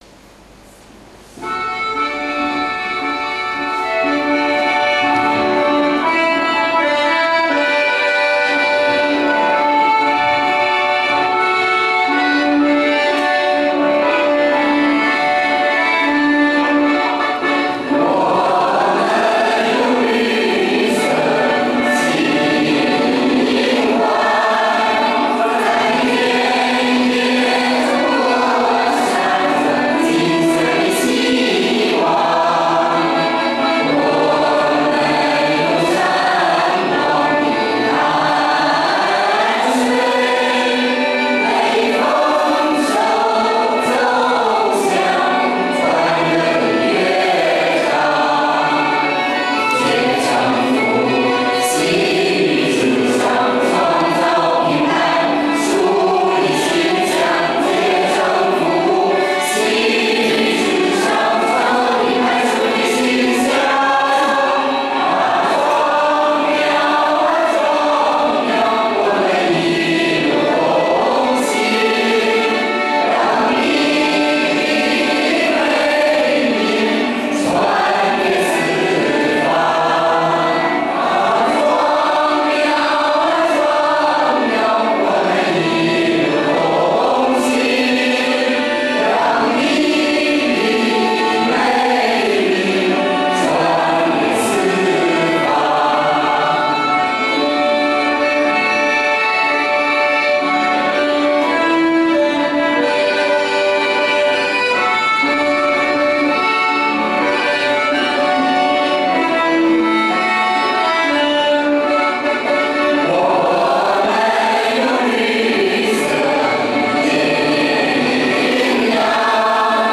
【点击下载3分钟完整版合唱MP3】